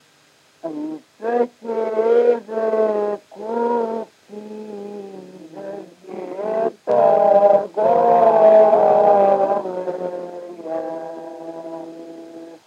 /е”-що т’ее”-же-куу-пц’иии” да-вс’ее” та-ргоооо”-вы-йа :/